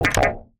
Universal UI SFX / Clicks
UIClick_Menu Reject Single 01.wav